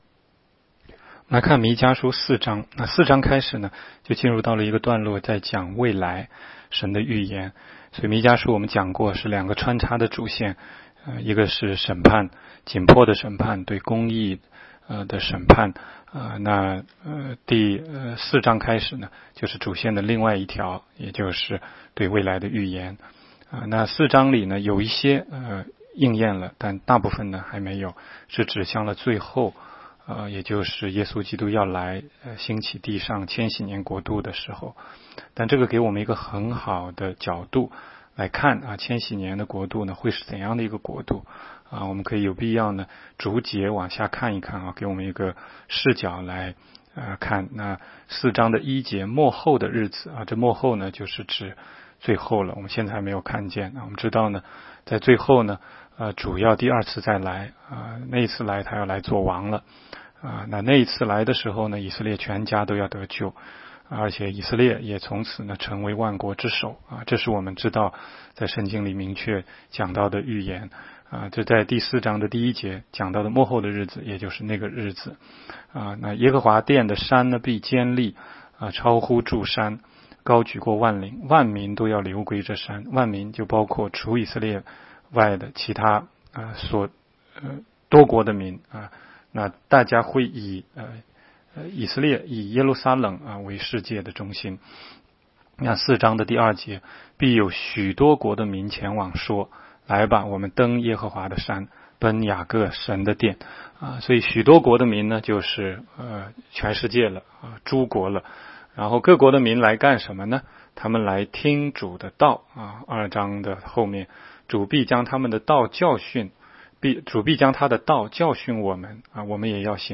16街讲道录音 - 每日读经 -《弥迦书》4章